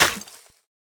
Minecraft Version Minecraft Version 1.21.5 Latest Release | Latest Snapshot 1.21.5 / assets / minecraft / sounds / block / suspicious_gravel / break4.ogg Compare With Compare With Latest Release | Latest Snapshot
break4.ogg